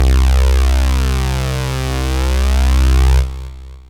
SYNTH BASS-2 0009.wav